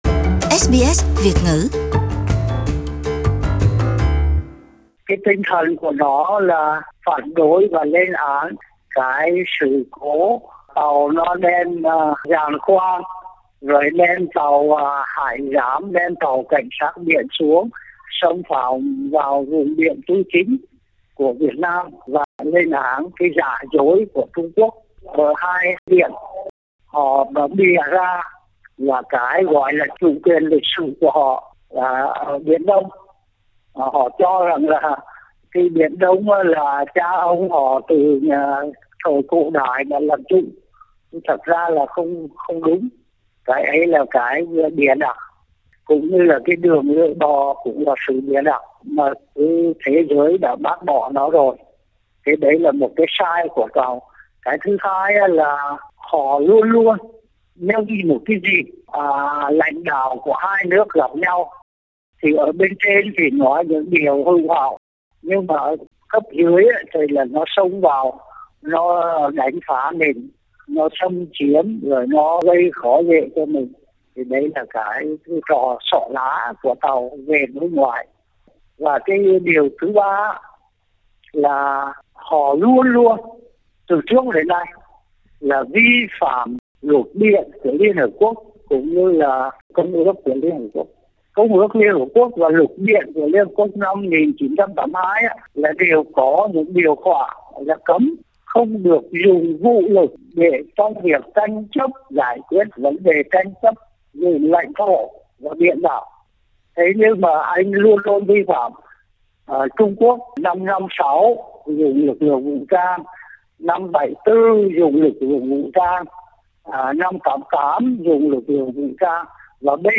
Tuyên bố đề cập đến việc Trung Quốc ngang nhiên đưa tàu thăm dò Hải Dương địa chất 8 với sự hộ tống của nhiều tàu hải giám và dân quân biển xâm nhập Vùng Đặc quyền kinh tế và Thềm lục địa Việt Nam tại khu vực bãi Tư Chính, Vũng Mây; đồng thời, đưa ra yêu cầu mạnh mẽ với chính phủ Việt Nam nhằm phát huy tinh thần đoàn kết dân tộc để vượt qua tình hình nguy hiểm hiện nay. Mời bấm vào file audio để nghe toàn văn bài phỏng vẫn của SBS Việt ngữ